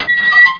gateopn.mp3